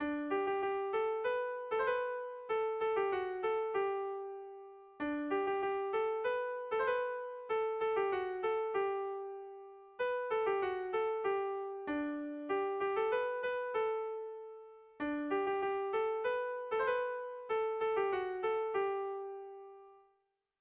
Bertso melodies - View details   To know more about this section
Sentimenduzkoa
AABA